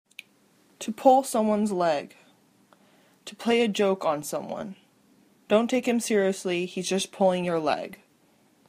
英語ネイティブによる発音は下記のリンクをクリックしてください。
topullsomeonesleg.mp3